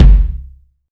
KICK.50.NEPT.wav